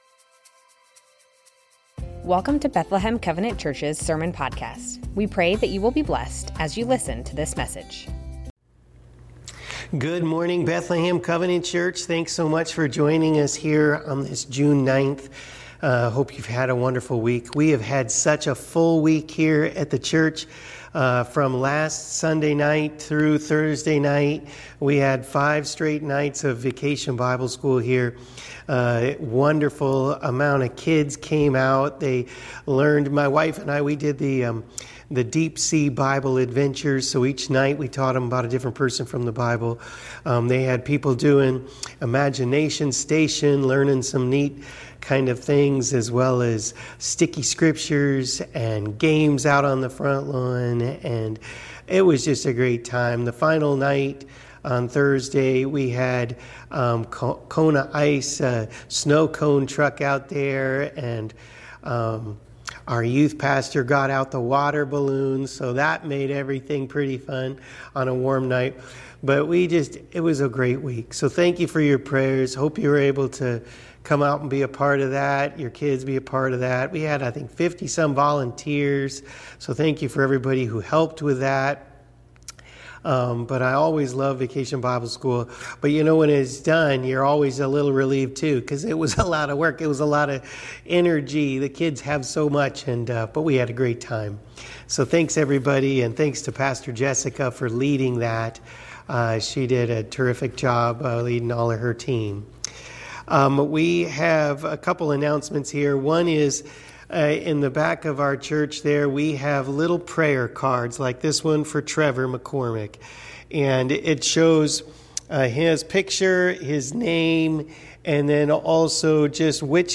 Bethlehem Covenant Church Sermons James - Two kinds of wisdom Jun 09 2024 | 00:30:25 1x 00:00 / 00:30:25 Subscribe Share Spotify RSS Feed Share Link Embed